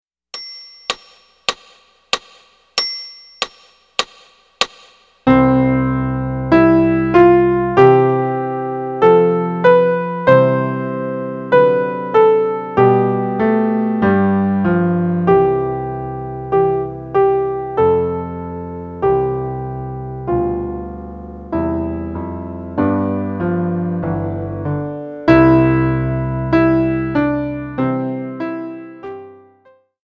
Piano/Organ Solo Piano Music Sacred
Piano